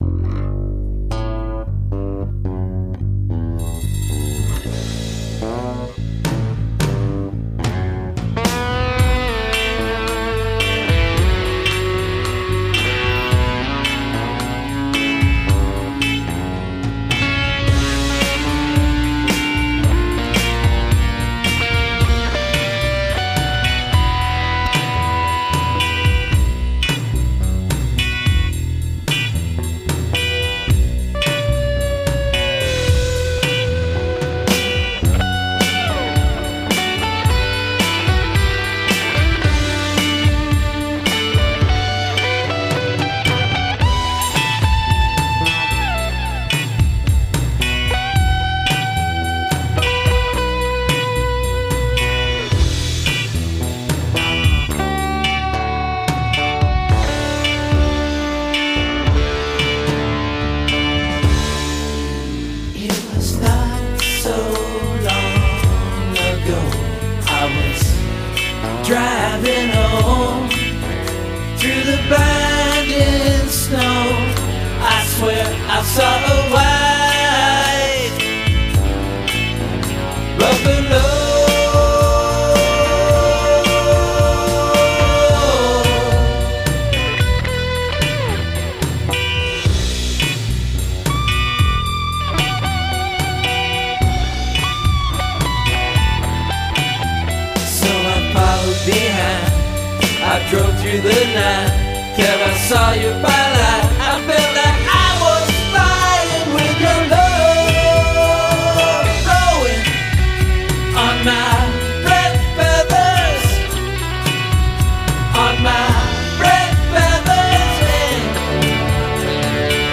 Drums and Percussion